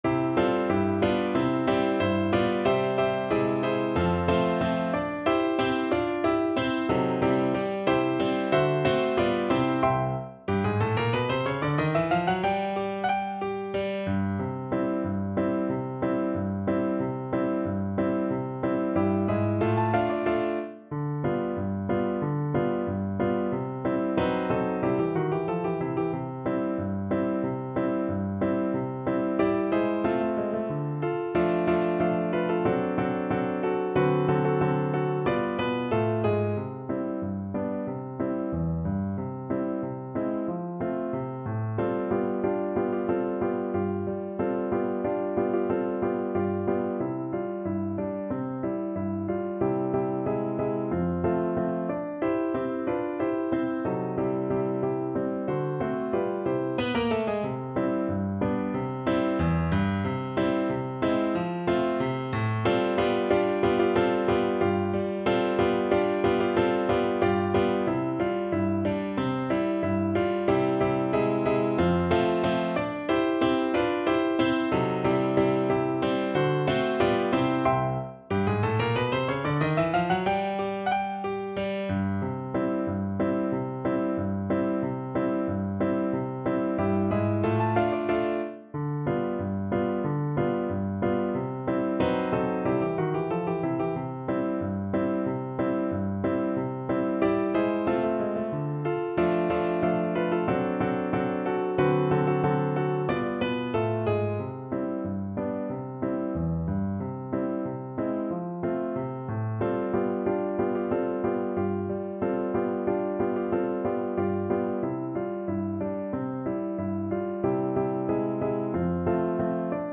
Allegretto =92
Traditional (View more Traditional Flute Music)